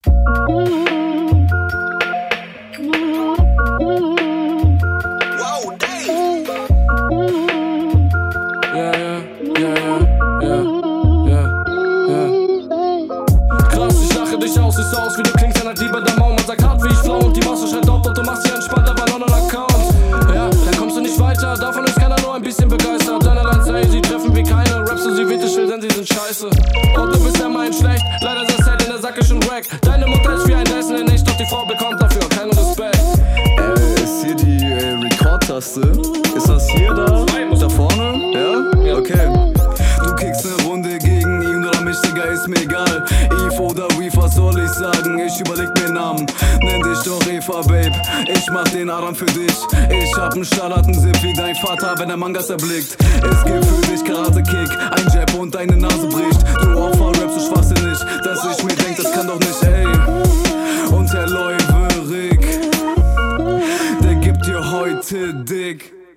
is juti. nur stimme bisschen leise im mix. hatte echt hin und wieder probleme zu …